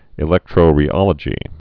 (ĭ-lĕktrō-rē-ŏlə-jē)